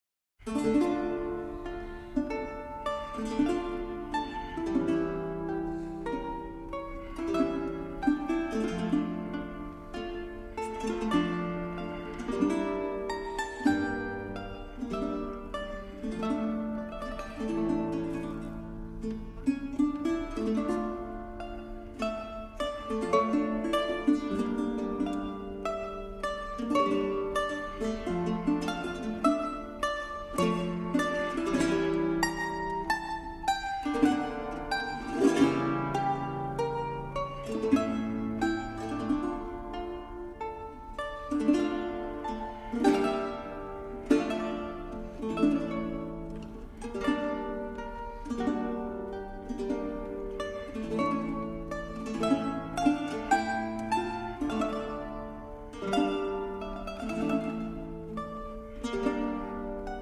Early music by Sanz
Chamber Ensemble